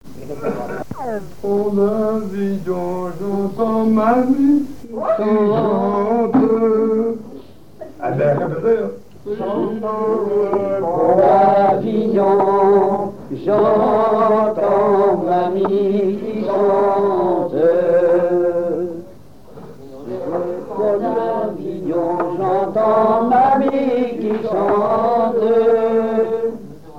Genre strophique
chansons à danser ronds et demi-ronds
Pièce musicale inédite